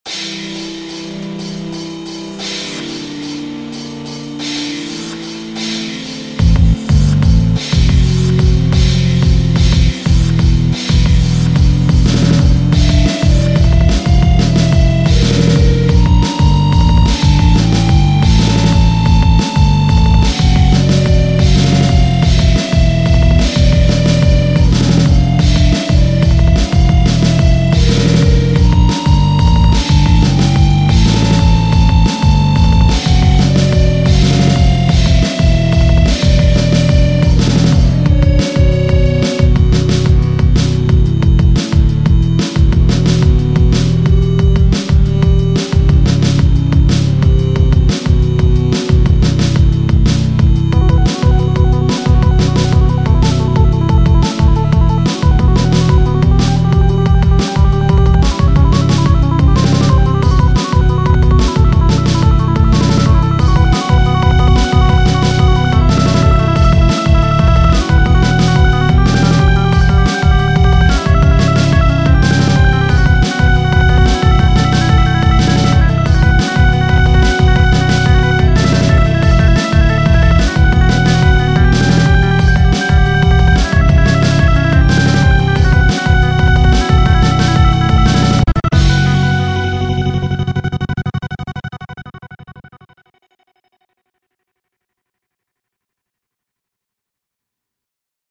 Effectivement, j'entend pas mal le côté Méchanique/Robotique mais j'aurai vu une track un peu plus "épique" pour le côté Divin.
Cependant, la compo est pas mal courageuse et bien rythmée, j'aime bien, bonne basse bien dynamique. Ça fait pas mal musique de jeux vidéo je trouve :D
Le fait d’avoir choisi un style électronique me semble vraiment pertinent pour le côté mécanique, d’autant plus que certains choix de sons sont judicieux (certains sons de synthés qui rappellent l’électricité, ou le fait d’avoir ajouté une batterie par exemple).
Les effets de stutter à la fin sont une très bonne idée à mon avis.